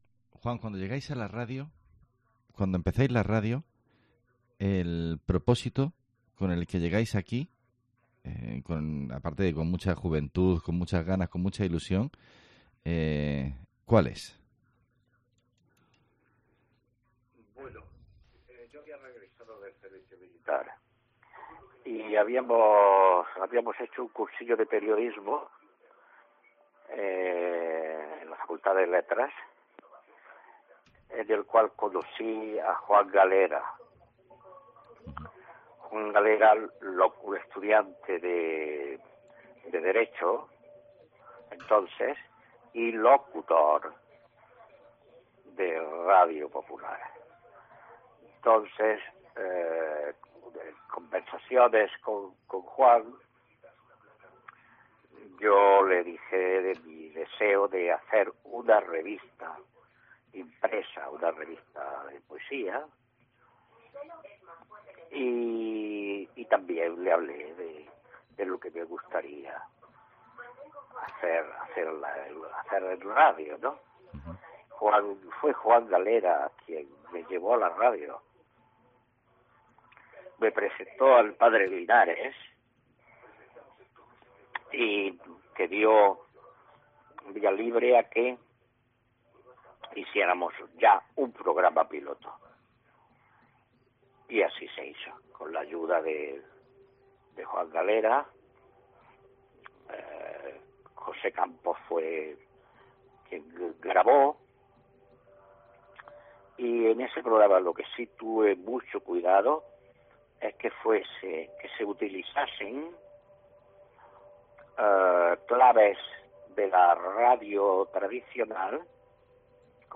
La última entrevista